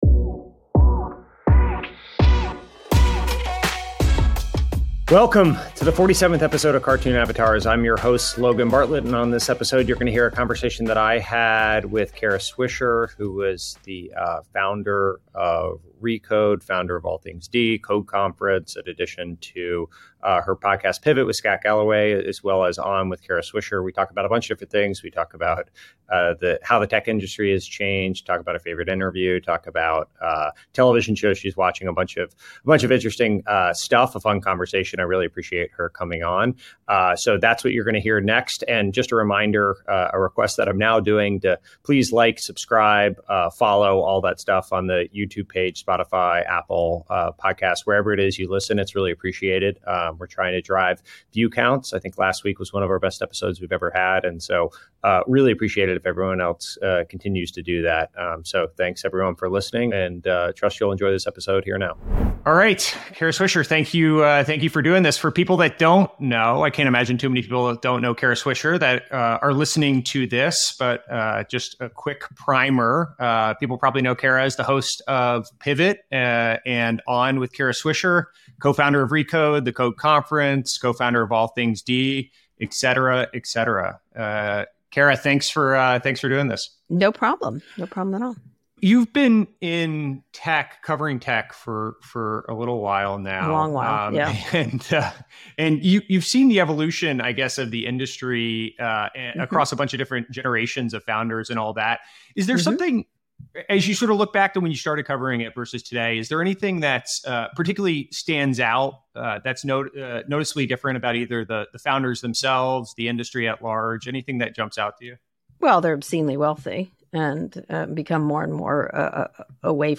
In the 47th episode journalist and host of the Pivot podcast, Kara Swisher joins to discuss the changes in tech and founders from when she started vs today. Plus the art of interviewing, propaganda vs journalism, the importance of being true to yourself and not caring what people think.